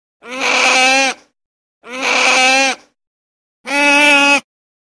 Крик морской выдры